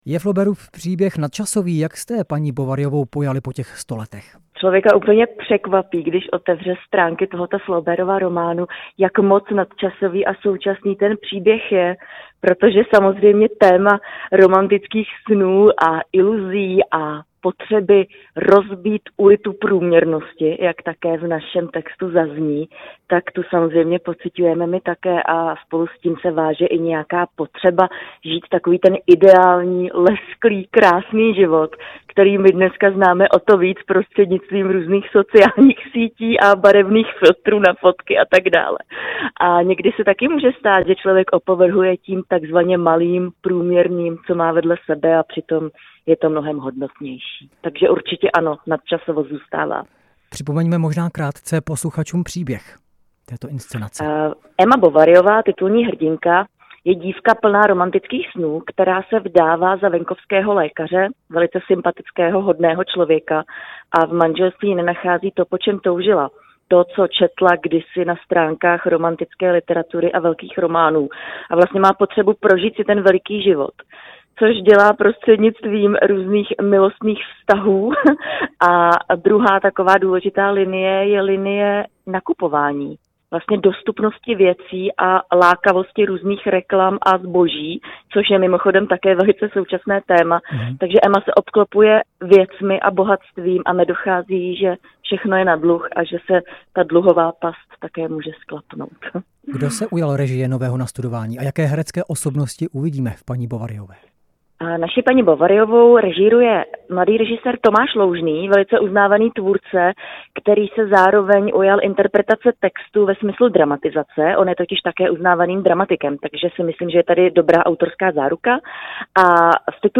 O návratu Madame Bovary jsme si povídali